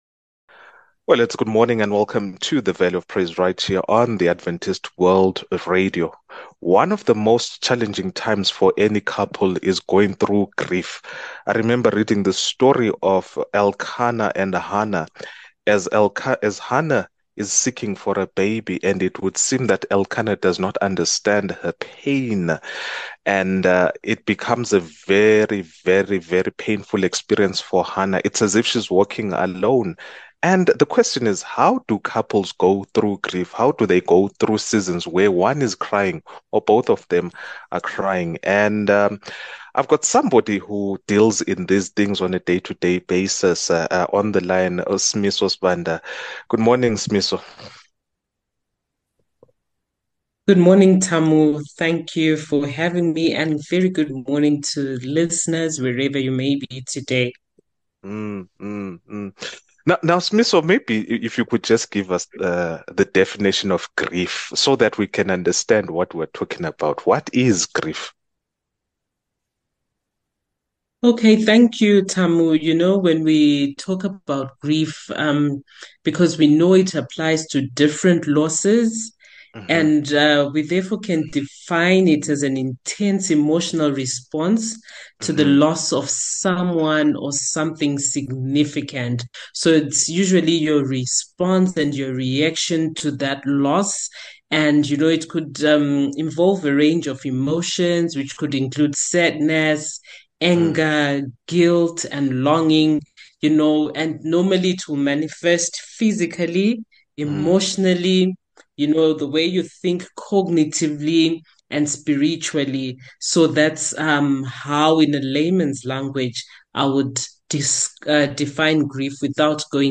We’ll discuss the emotional and psychological impact of this loss, as well as coping strategies for healing. Join us for an empathetic conversation aimed at providing support and understanding for those navigating this difficult journey.